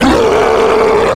One of Bowser's voice clips in Mario Strikers Charged
MSCBowserRoar2.oga